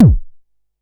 Index of /musicradar/essential-drumkit-samples/Vermona DRM1 Kit
Vermona Kick 01.wav